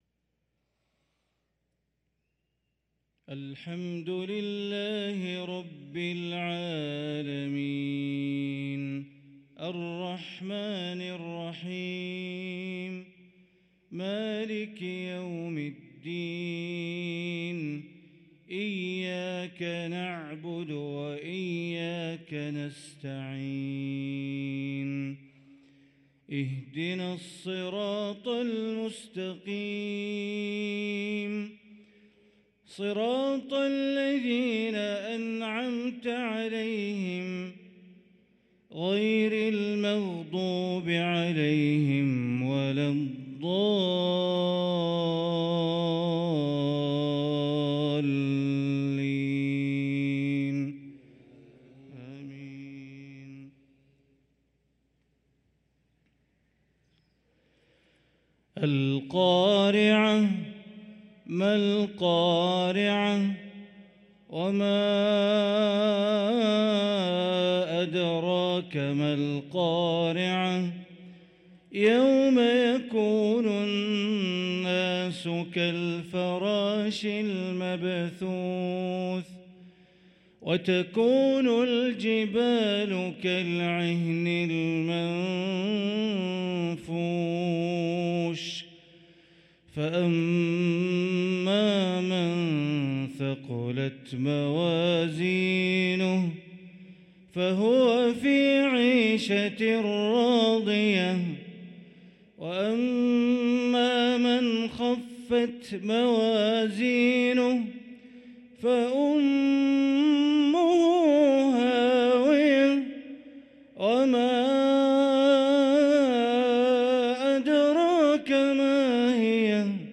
صلاة المغرب للقارئ بندر بليلة 14 صفر 1445 هـ